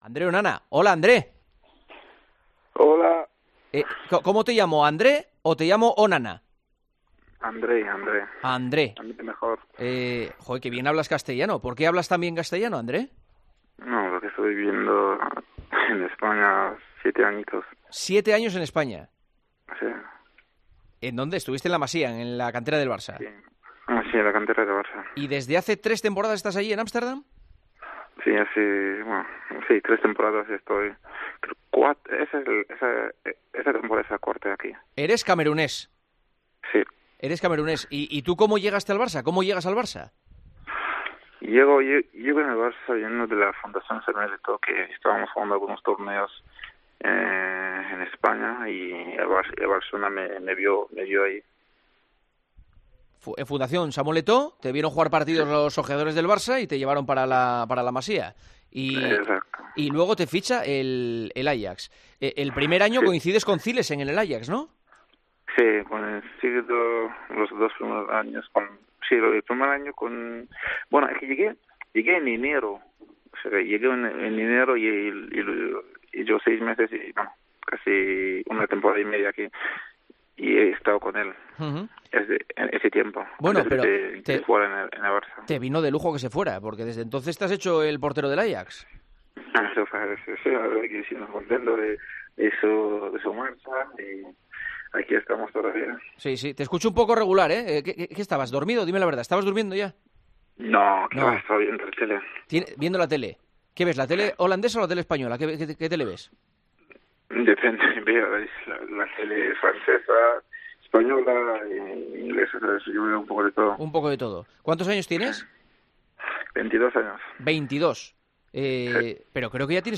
En El Partidazo de COPE, hablamos con el portero camerunés Andre Onana: "No creo que tengamos muchas opciones de ganar.